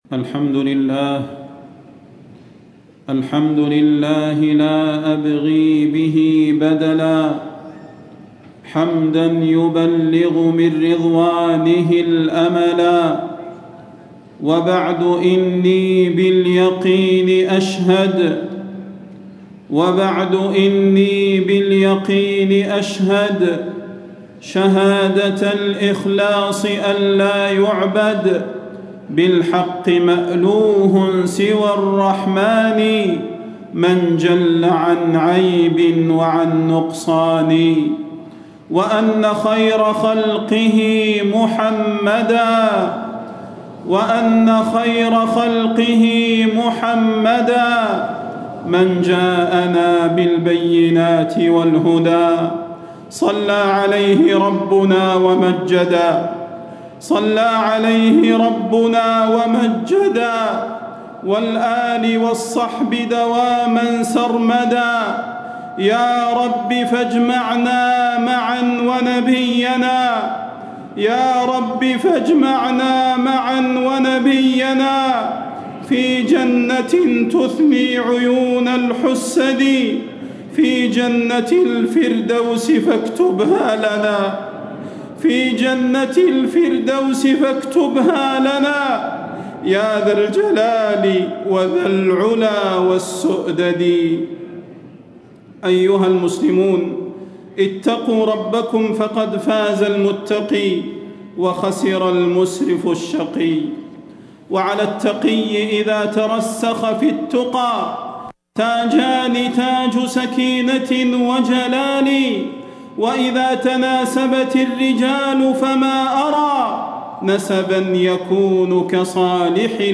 تاريخ النشر ١٢ رجب ١٤٣٦ هـ المكان: المسجد النبوي الشيخ: فضيلة الشيخ د. صلاح بن محمد البدير فضيلة الشيخ د. صلاح بن محمد البدير التوبة إلى الله تعالى